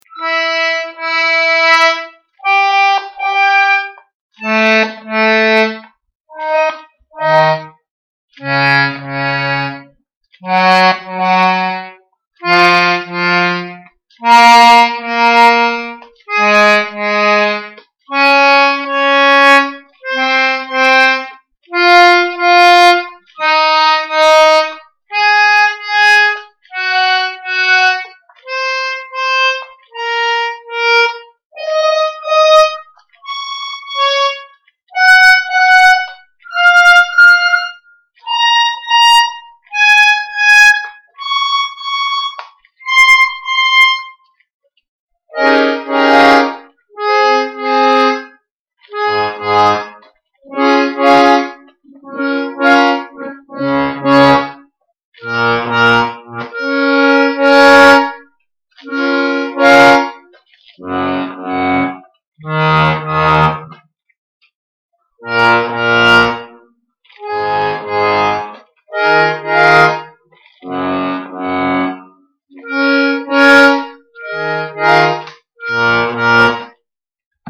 Гармонь.mp3